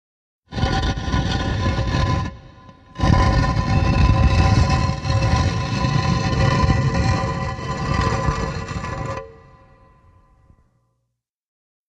Скрип открывающейся крышки канопы